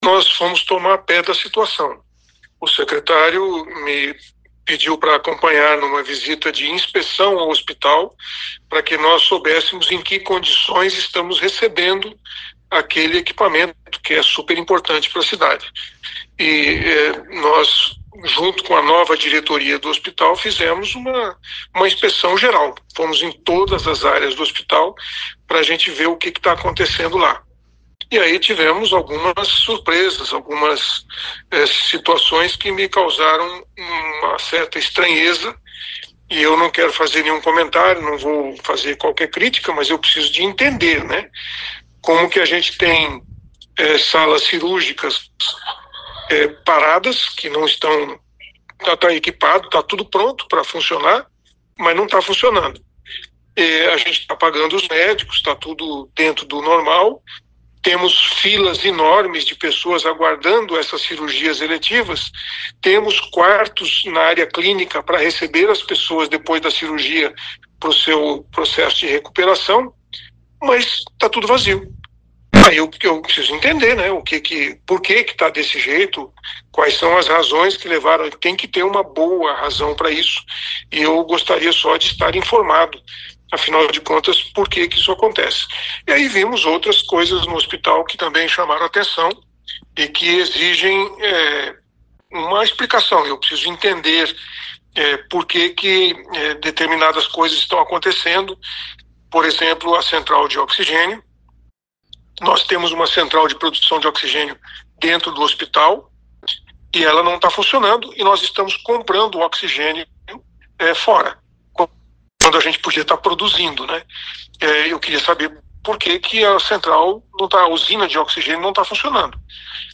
Outro problema detectado é a central de oxigênio, também sem uso; enquanto o hospital precisa comprar oxigênio medicinal para os pacientes. Ouça o que diz o prefeito Sílvio Barros em relação ao que foi constatado no Hospital Municipal: